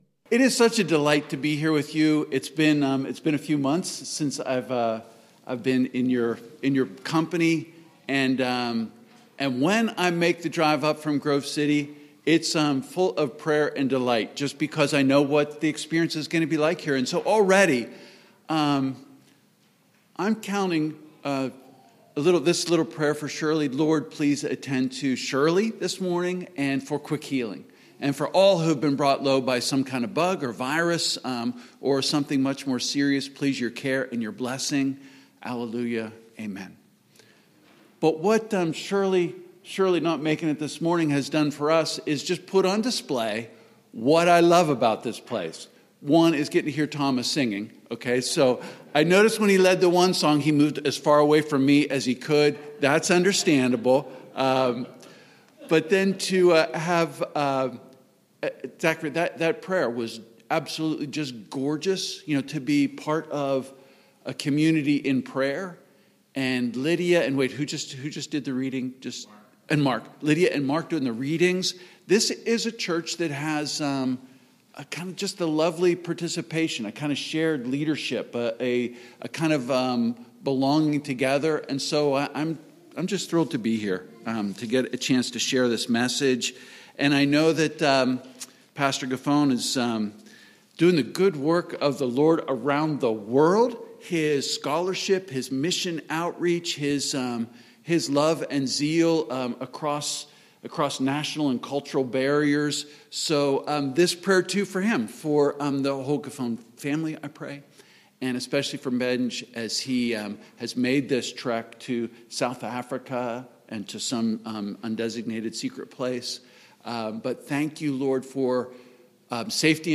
Fourth Sunday in Lent
Sermon